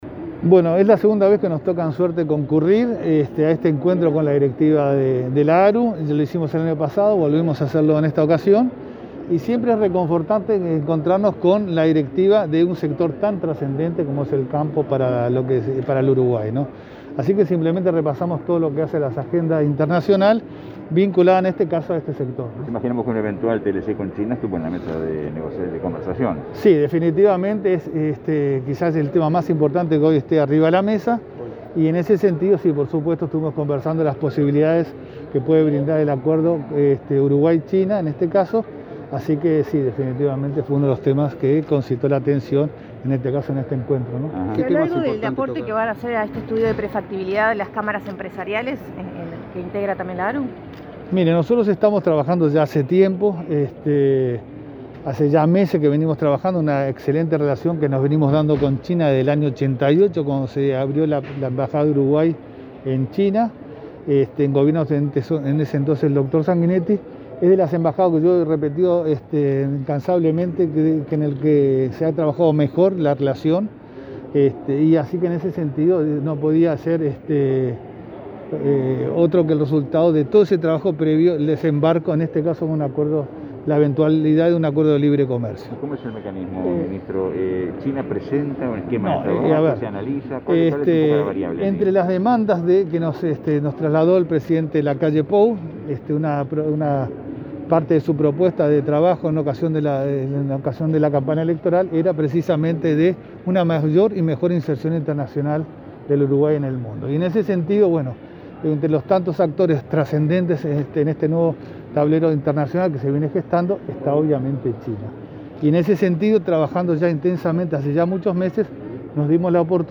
Declaraciones del ministro de Relaciones Exteriores, Francisco Bustillo, a la prensa
Tras almorzar con integrantes de la Asociación Rural del Uruguay en la Expo Prado 2021 este 16 de setiembre, el canciller de la República efectuó